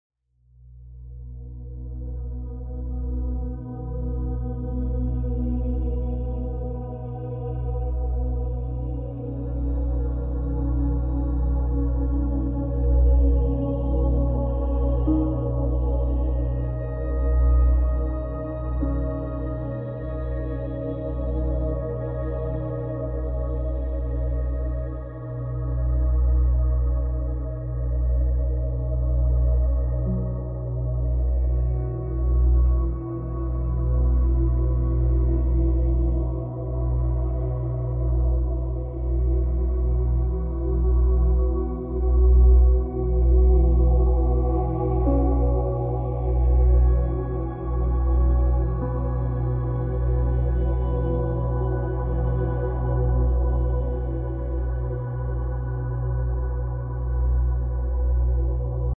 Brain massage 8D audio. Relax sound effects free download
Relax your mind with 8D music.